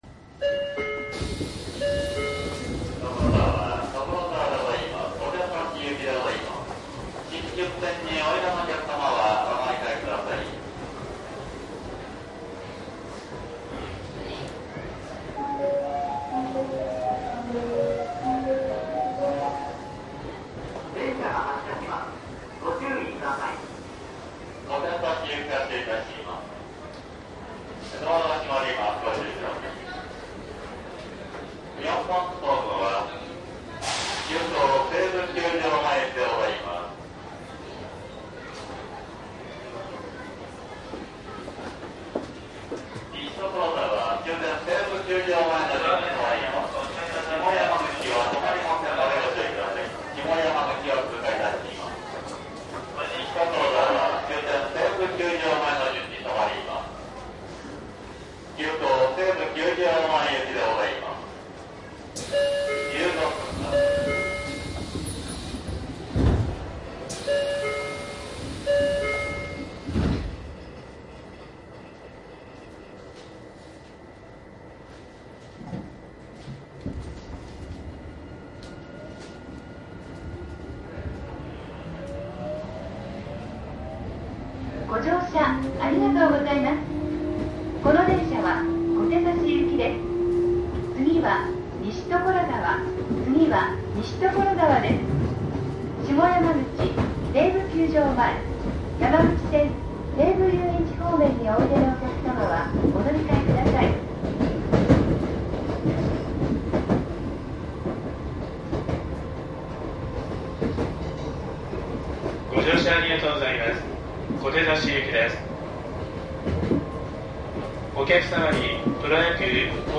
♪西武有楽町線07系 走行音CD
こちらは07系1次車（制御装置三菱）の走行音です。石神井公園～練馬の間で子供の声が若干入ってます。うるさいと感じる部分は削りましたが車内放送と被ってる部分はそのままになってます。
■【各停】新線池袋→小竹向原→小手指 07系1次車 ＜DATE98-5-4＞MZR－3
マスター音源はデジタル44.1kHz16ビット（マイクＥＣＭ959）で、これを編集ソフトでＣＤに焼いたものです。